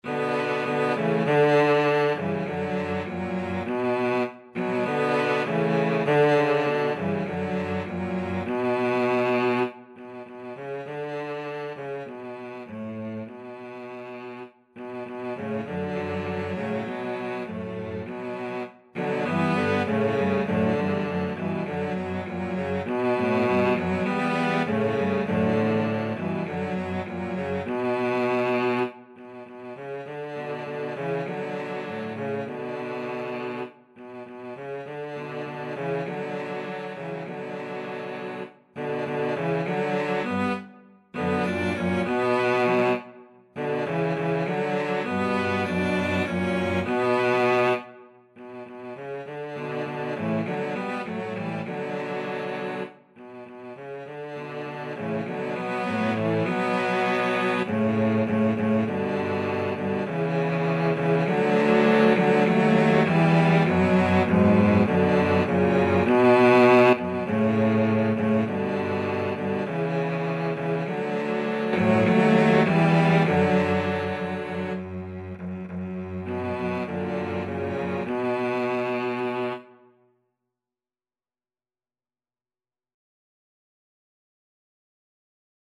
Free Sheet music for Cello Trio
2/4 (View more 2/4 Music)
Moderato
B minor (Sounding Pitch) (View more B minor Music for Cello Trio )
Classical (View more Classical Cello Trio Music)